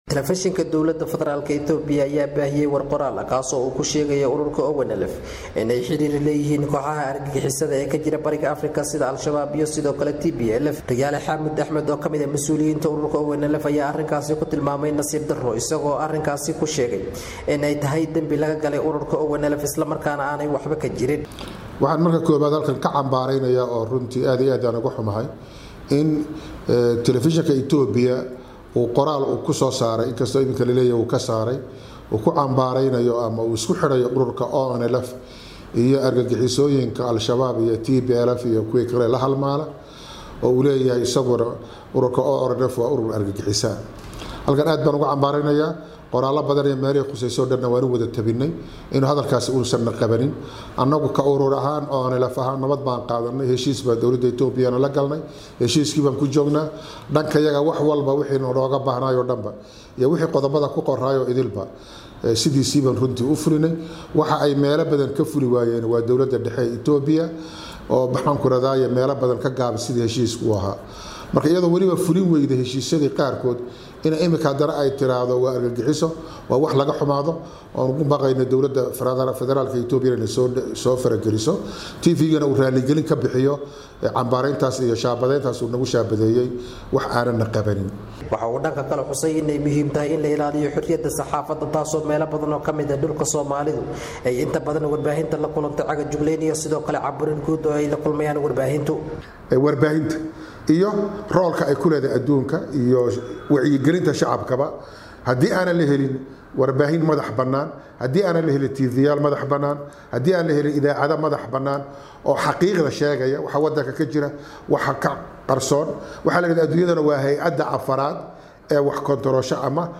ka soo diray magaalada Jigjiga ee dalka Itoobiya.